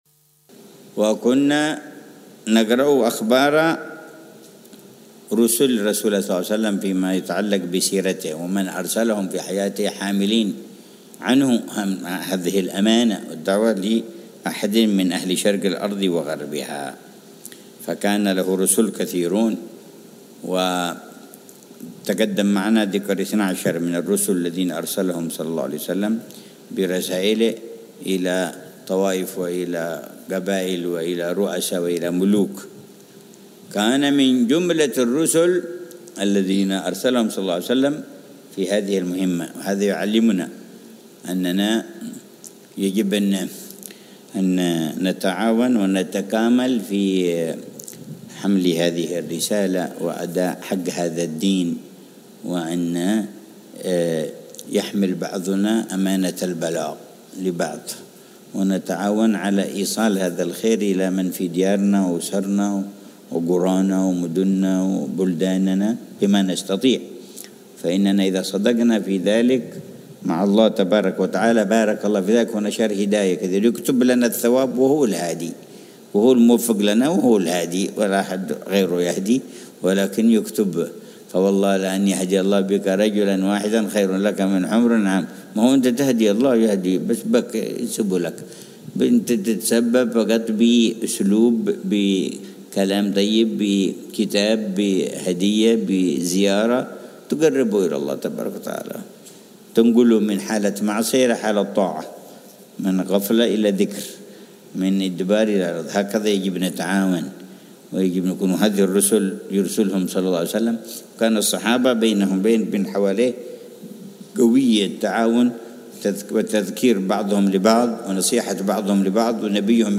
درس السيرة النبوية - رُسُل رسول الله ﷺ: الصحابي السائب بن العوام - 2 -